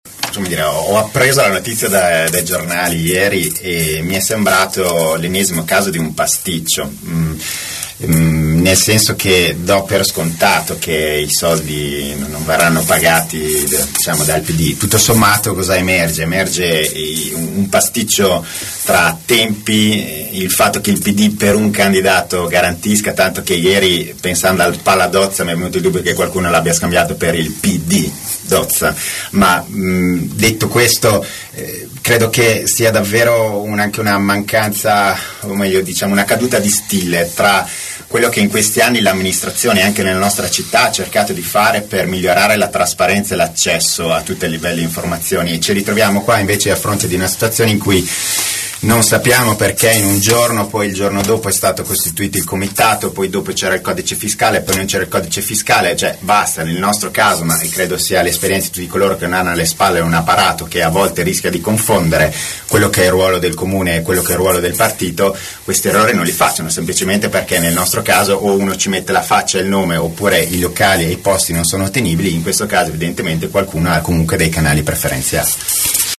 21 nov. – La prenotazione del palaDozza per la festa a sostegno di Pier Luigi Bersani, fatta dal Partito Democratico perché il comitato per Bersani non aveva ancora il codice fiscale, è “una caduta di stile” secondo il consigliere provinciale renziano Giovanni Maria Mazzanti, ospite questa mattina nei nostri studi per una tavola rotonda sulle primarie del centro sinistra.